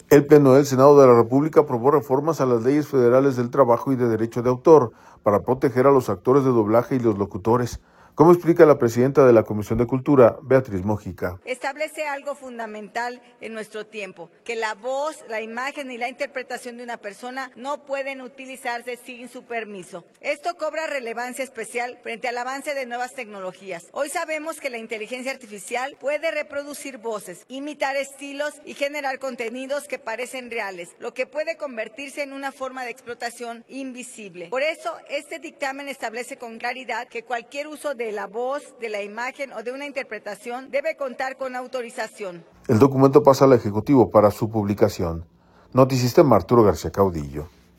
El Pleno del Senado de la República aprobó reformas a las leyes federales del trabajo y de derecho de autor, para proteger a los actores de doblaje y los locutores, como explica la presidenta de la Comisión de Cultura, Beatriz Mojica.